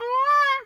bird_peacock_squawk_soft_08.wav